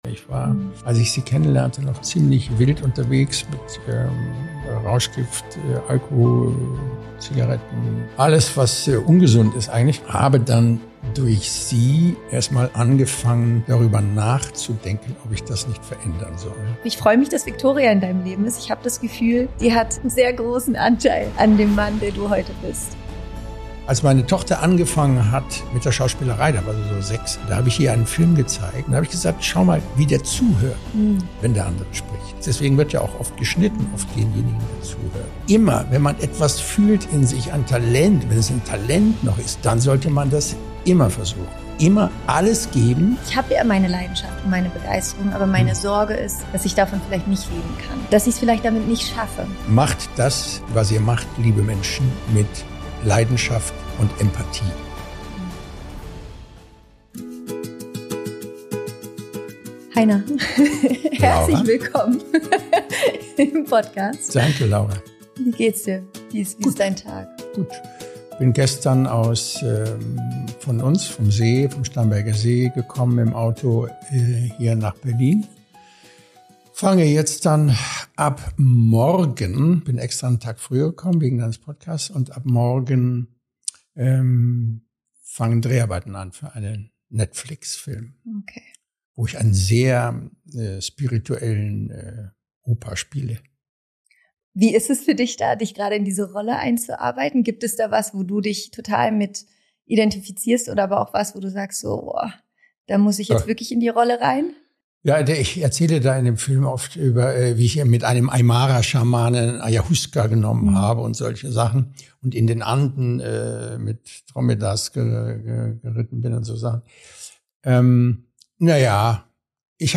In dieser besonderen Folge spreche ich mit Heiner Lauterbach, einem der bekanntesten Schauspieler Deutschlands, über Mut, Veränderung und den Weg zu einem erfüllten Leben.